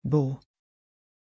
Pronunciation of Bo
pronunciation-bo-sv.mp3